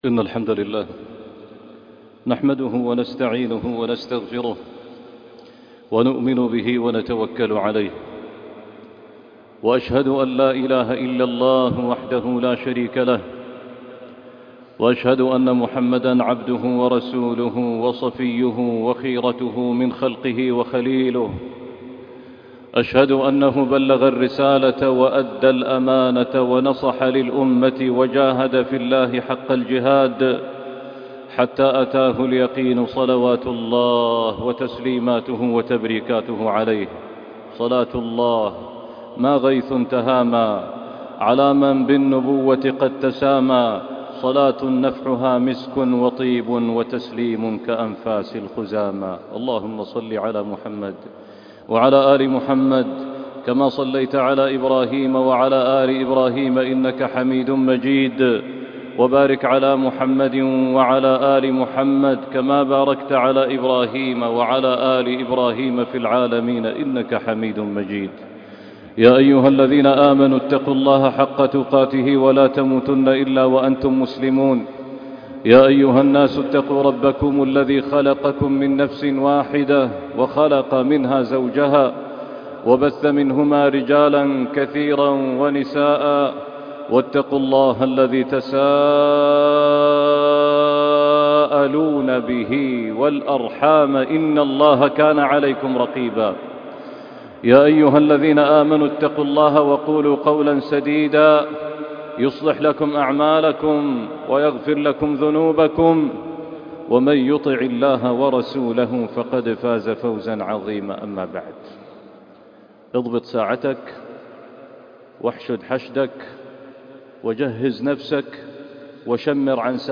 أعظم من رمضان!خطبة وصلاة الجمعة